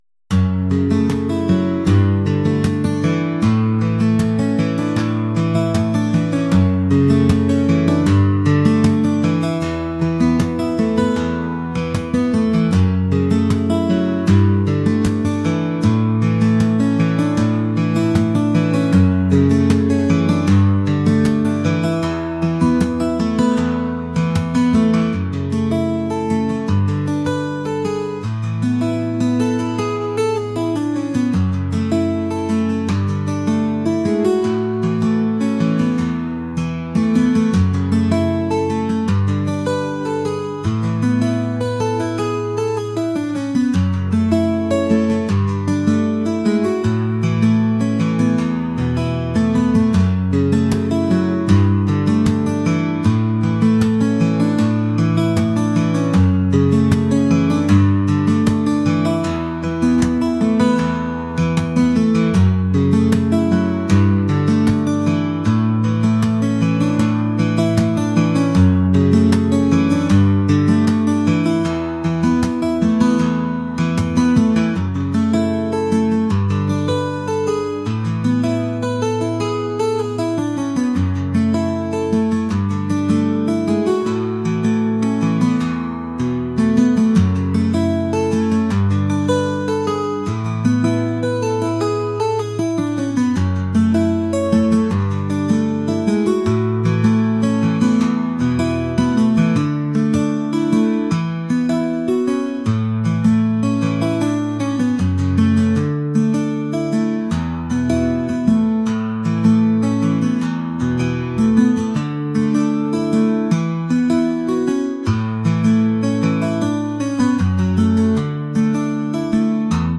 acoustic | folk | ambient